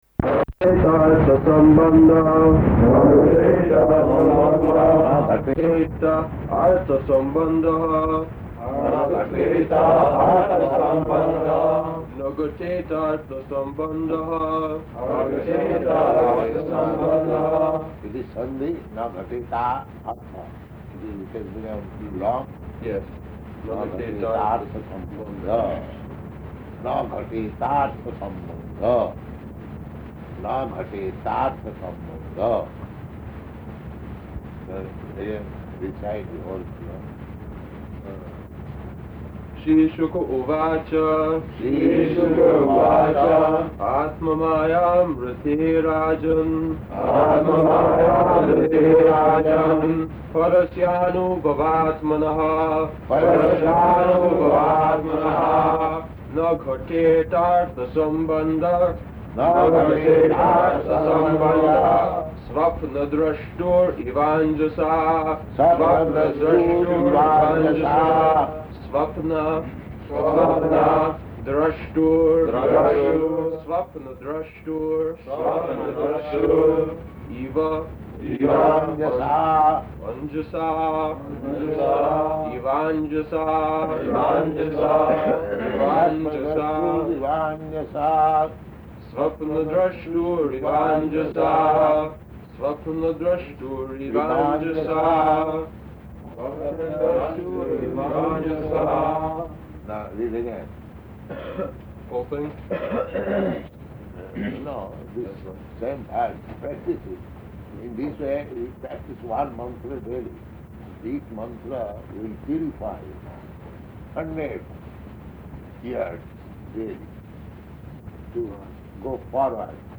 April 23rd 1972 Location: Tokyo Audio file
[devotees repeat] Prabhupāda: [indistinct] It is sandhi?